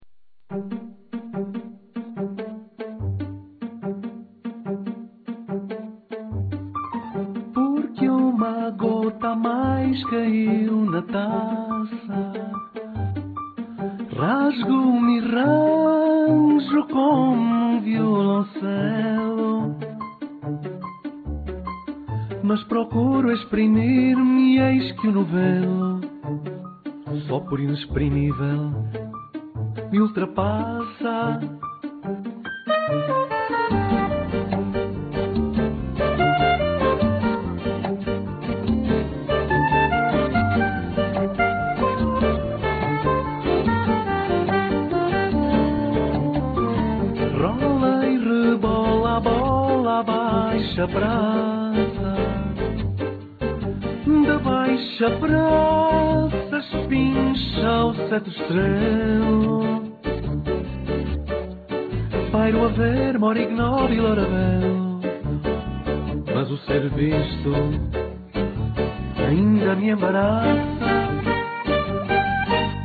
voz